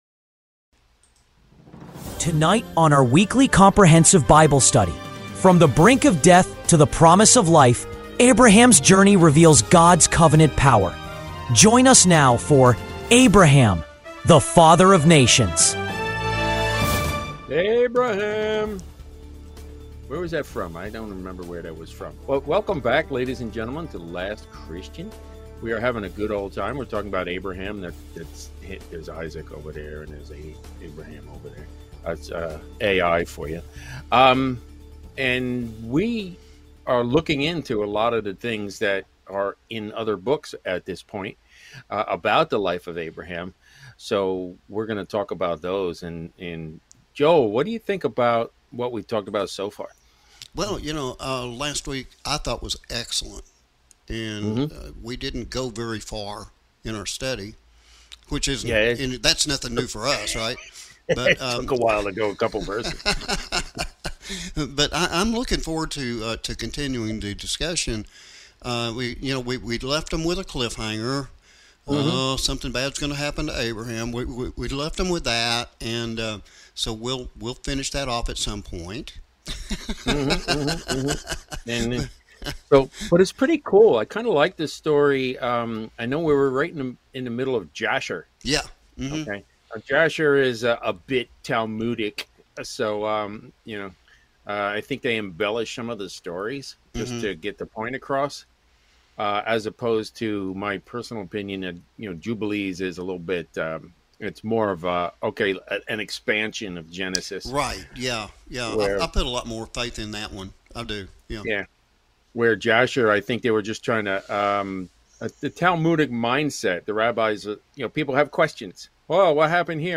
Abraham The Father Of Nations - Weekly Comprehensive Bible Study